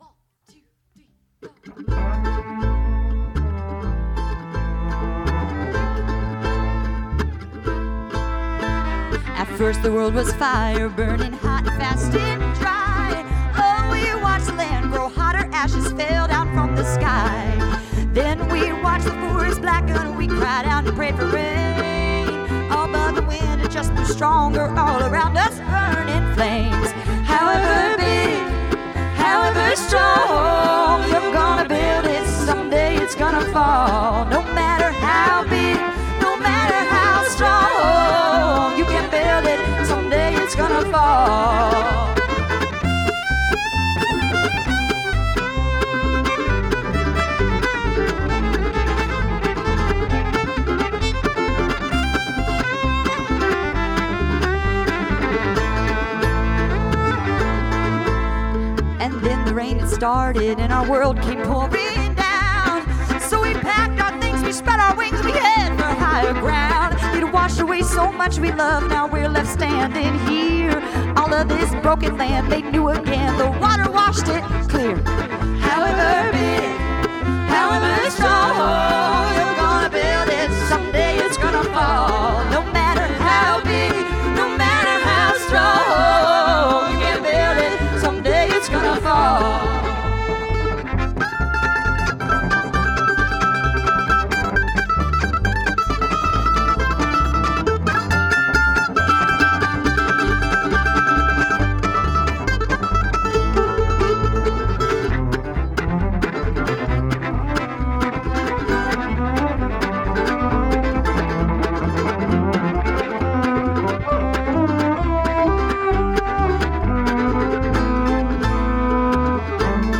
Double Bass, Guitar, Vocals
Mandolin, Guitar, Vocals
Fiddle, Vocals
Cello, Vocals